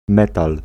Ääntäminen
Ääntäminen France: IPA: [œ̃ me.tal] Paris Tuntematon aksentti: IPA: /me.tal/ Haettu sana löytyi näillä lähdekielillä: ranska Käännös Ääninäyte Substantiivit 1. metal {m} Suku: m .